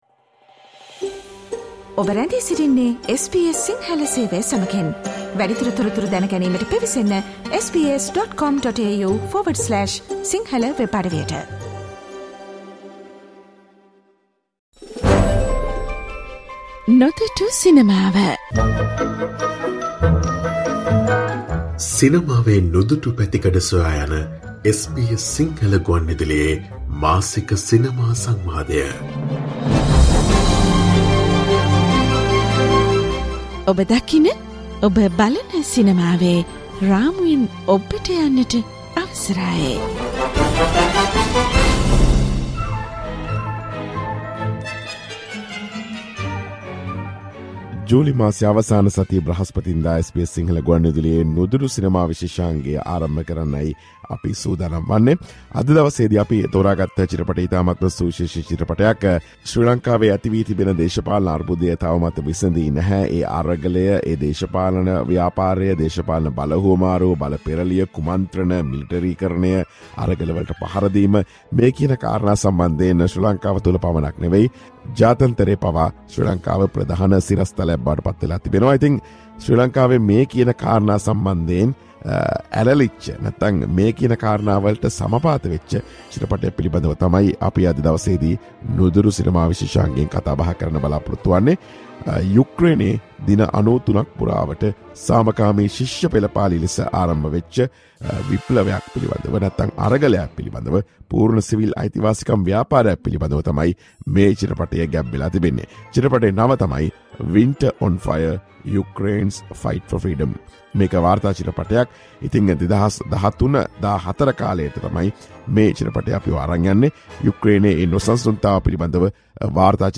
අප සමග කතා බහට